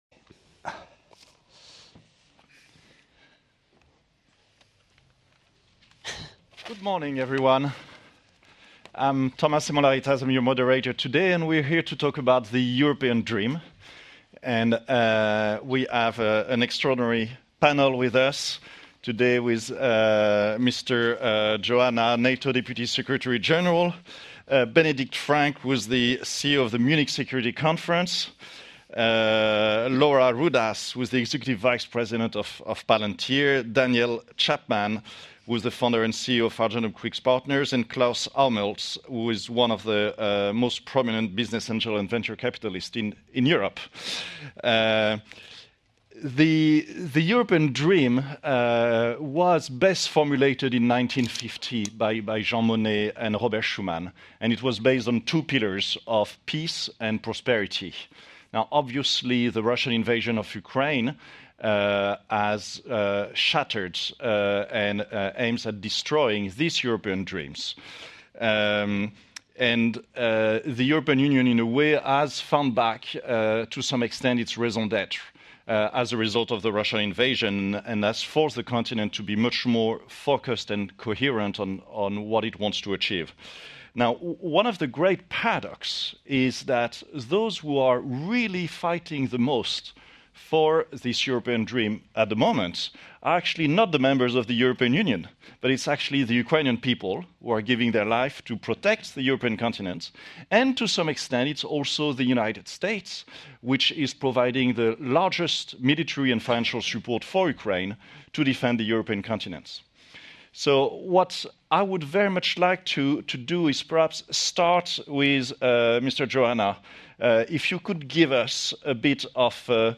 Remarks by NATO Deputy Secretary General Mircea Geoană at the Milken Institute Global Conference during the panel ‘From European Dream to Reality’
(As delivered)